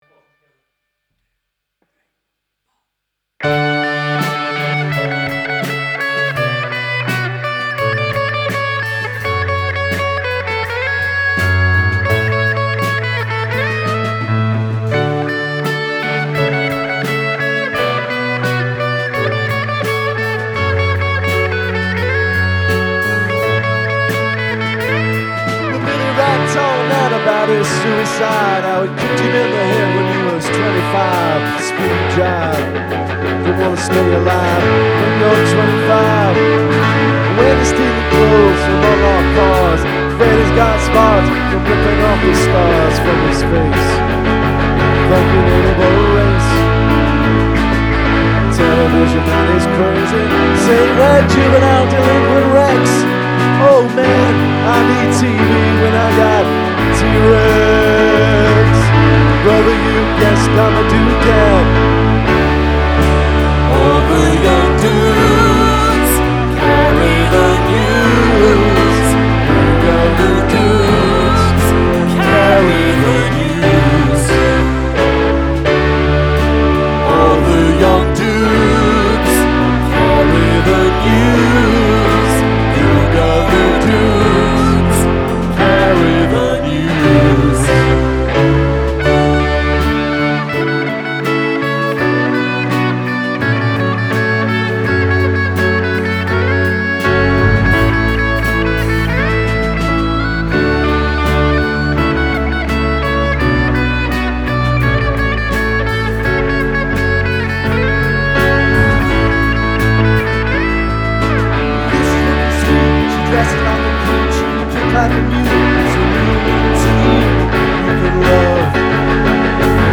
hitting the high, but not necessarily the right, notes ...